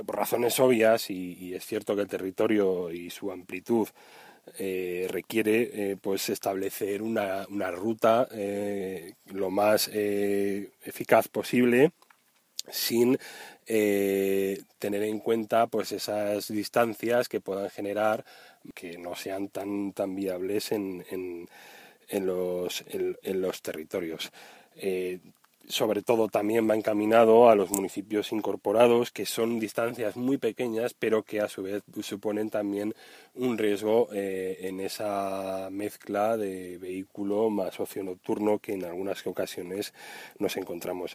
Audios de Enrique Novella, portavoz de C’s en la Comarca La Hoya de Huesca: